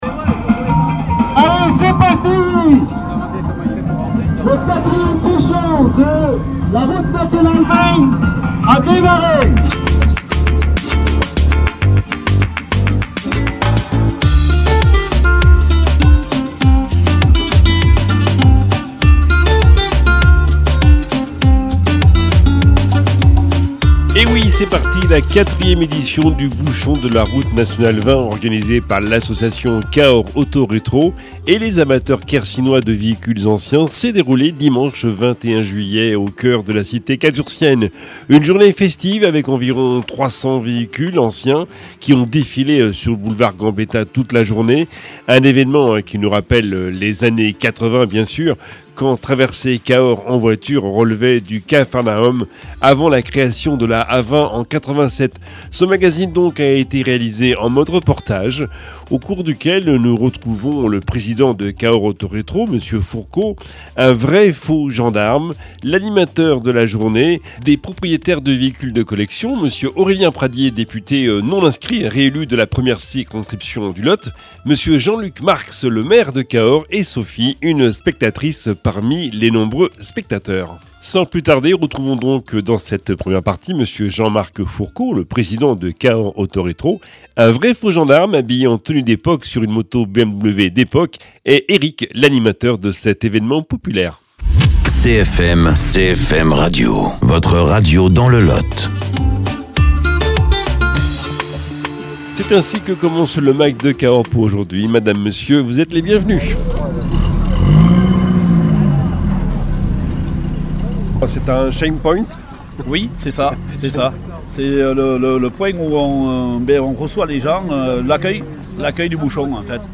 Un magazine réalisé en mode reportage..